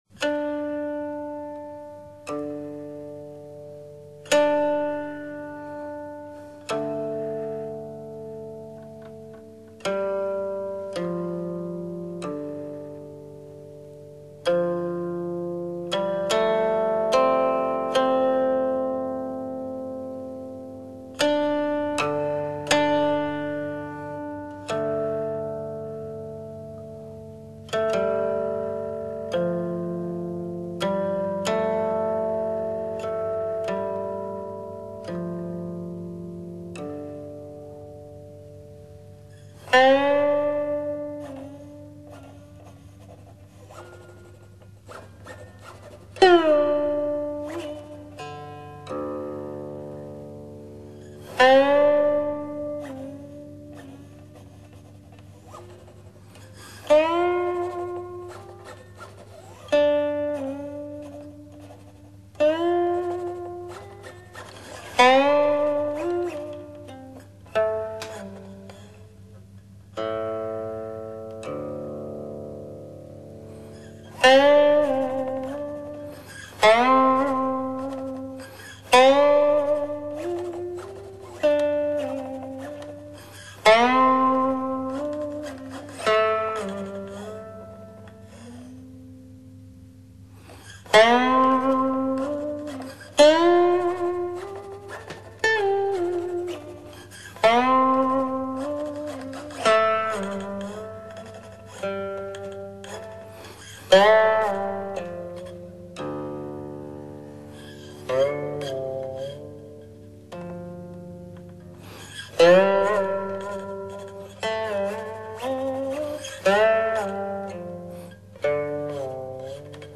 中国古琴名家演奏经典
音乐类型：民乐